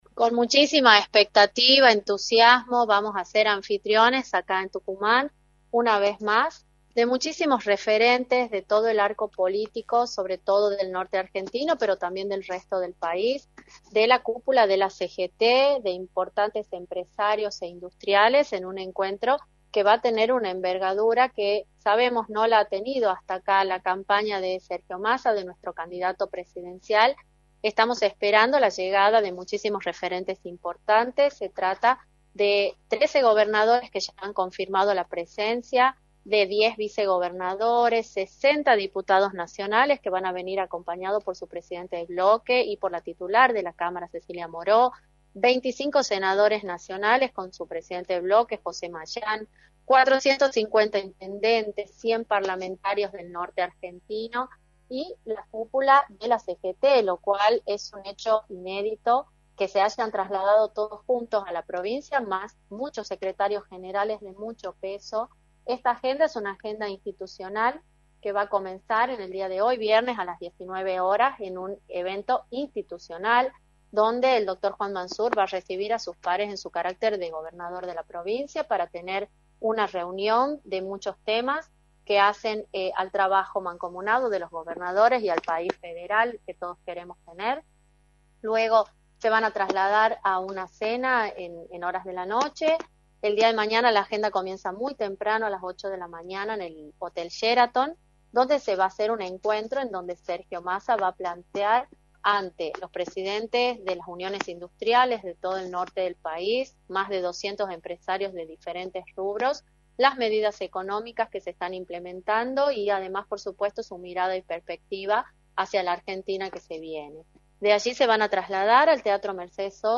Carolina Vargas Aignasse, Ministra de Gobierno y Justicia, remarcó en Radio del Plata Tucumán, por la 93.9, cuales son las expectativas del gobierno ante la llegada del candidato a Presidente, Sergio Massa.